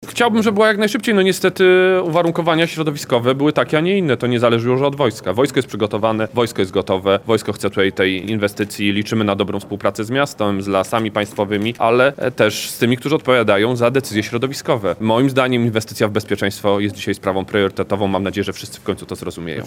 Budowa Stałej Bazy Lotnictwa Wojsk Lądowych przy lotnisku w Świdniku należy do priorytetów Ministerstwa Obrony Narodowej, a opóźnienia w realizacji tej inwestycji wynikają z uwarunkowań środowiskowych, na które MON nie ma wpływu – powiedział w Lublinie wicepremier szef MON Władysław Kosiniak-Kamysz.